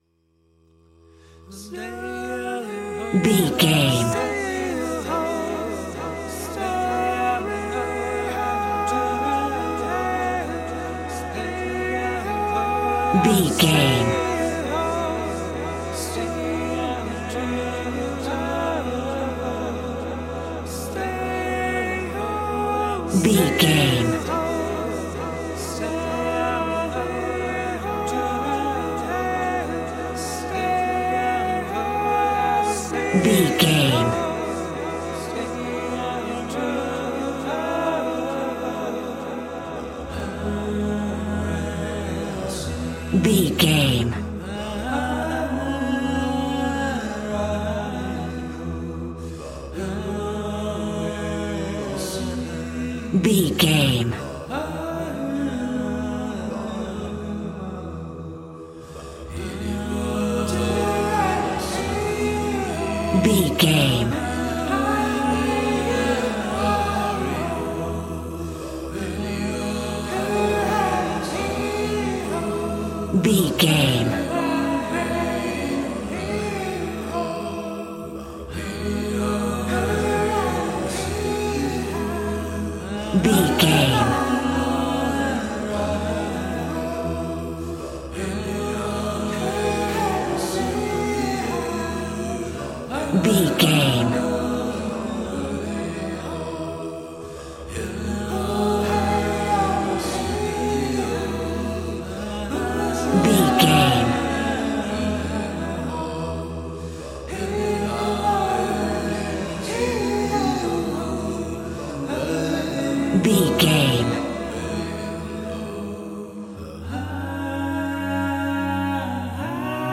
Aeolian/Minor
A♭
groovy
inspirational